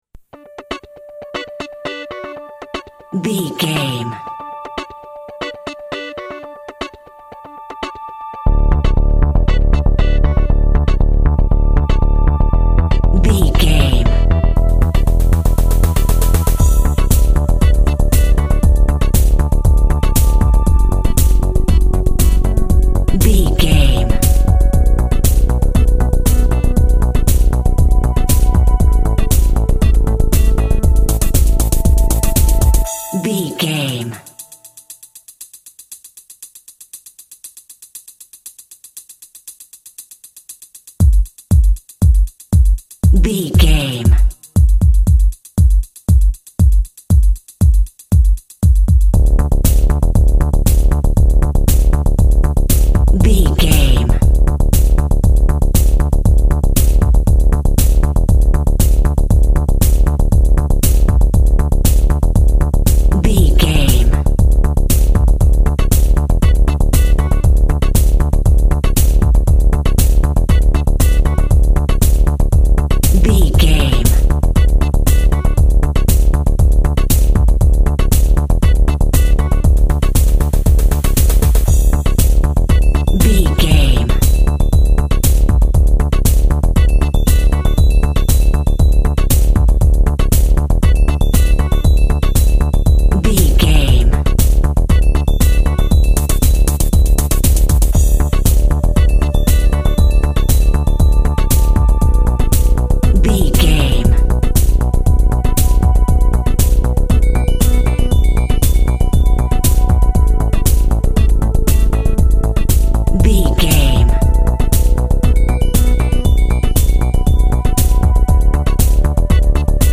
Funky Electronic Music.
Ionian/Major
D
funky
groovy
uplifting
futuristic
energetic
cheerful/happy
bass guitar
electric guitar
drums
synthesiser
electric piano